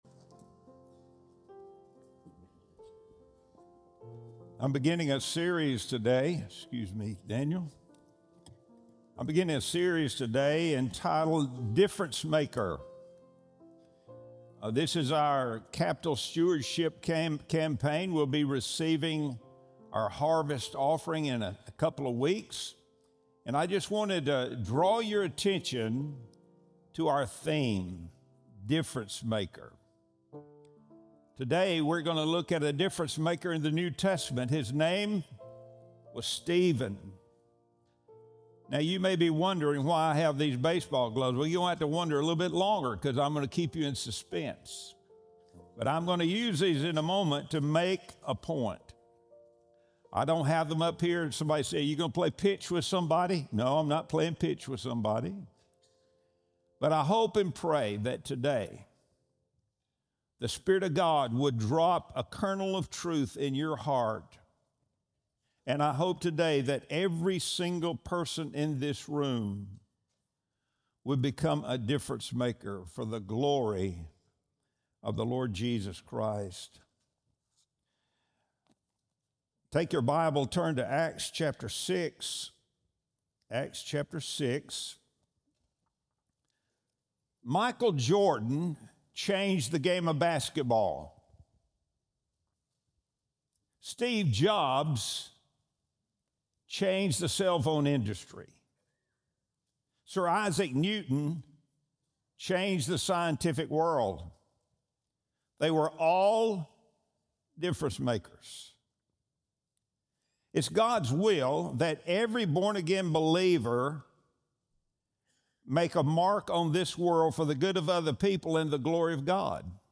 Sunday Sermon – November 9, 2025